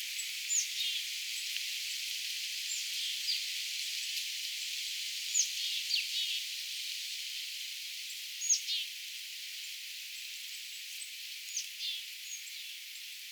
tuollaista hömötiaislinnun huomioääntelyä
erilaista_uudenlaista_homotiaisen_huomioaantelya.mp3